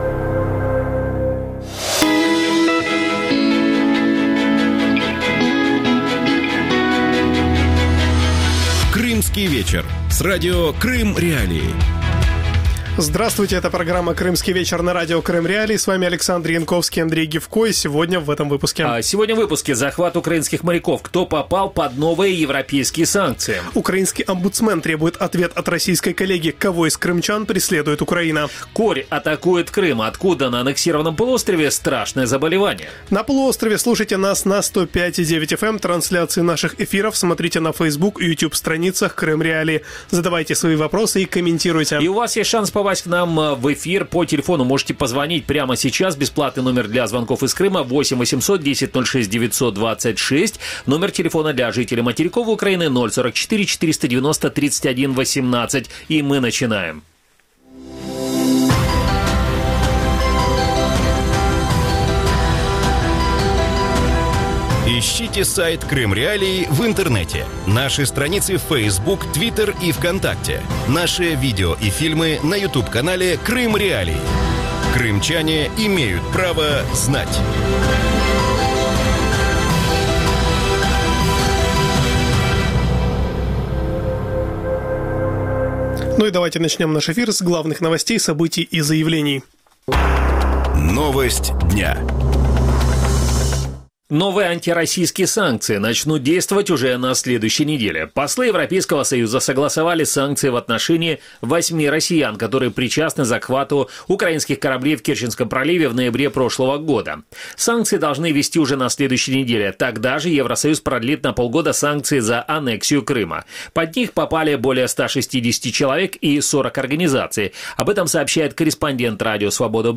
в студии Радио Крым.Реалии в ток-шоу